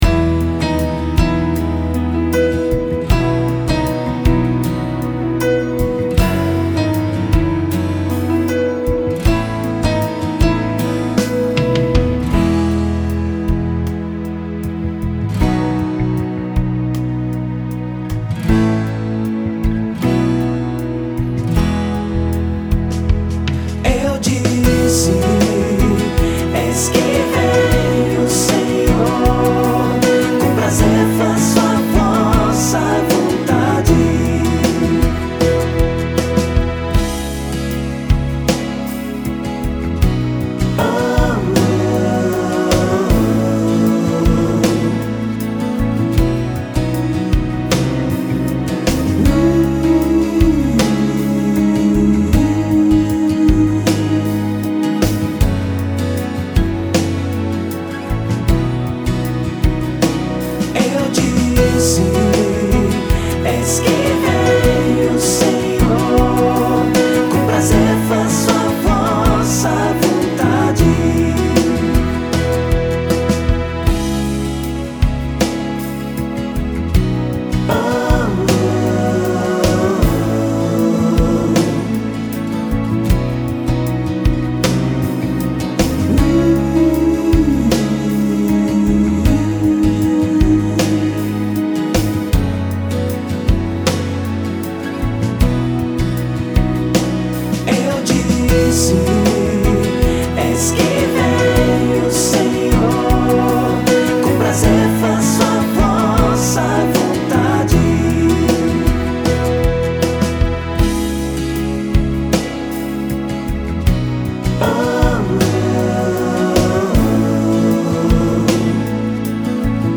Playback (música) - Salmo 39
salmo_39_5_playback.mp3